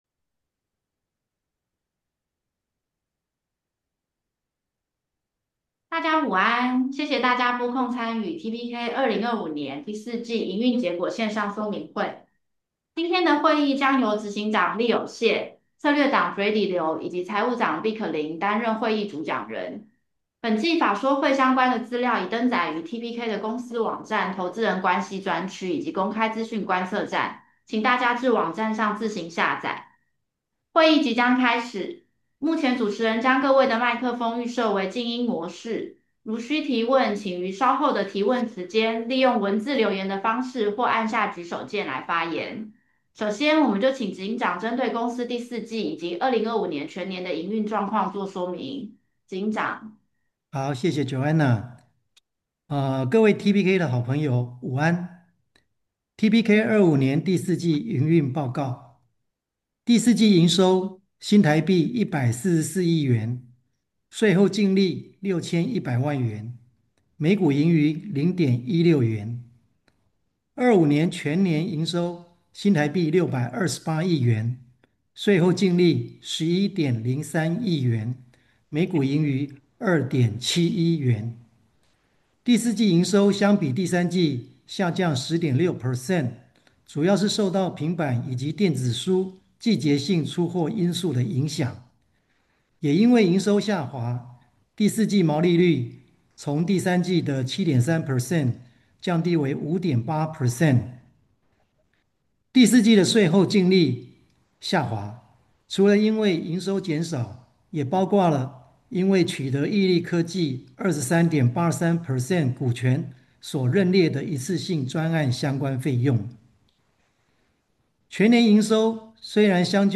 TPK Q4 2025 Investor Conference